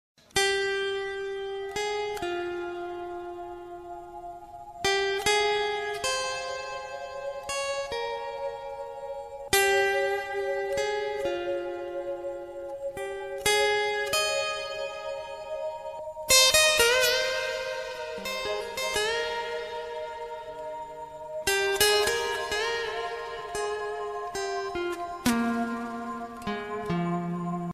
Country Ringtones